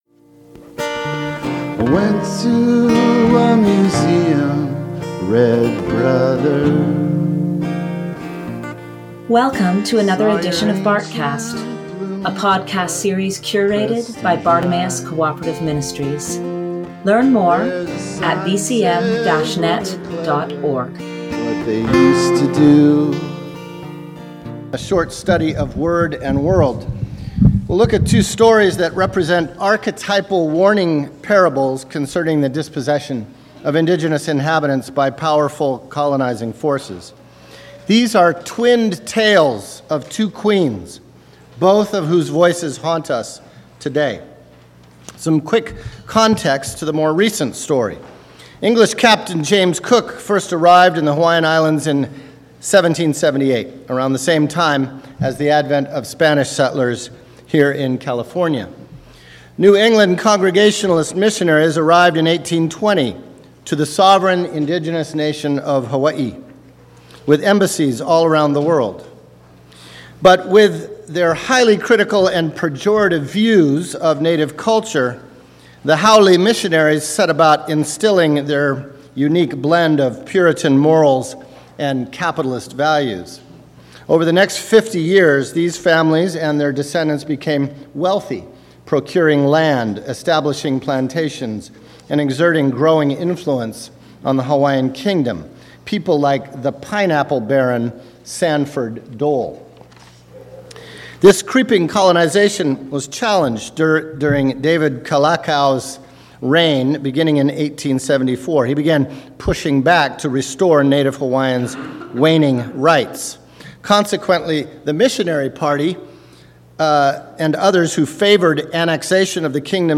Bartcast 44: BKI2020 Tuesday Bible Study – Naboth’s nahala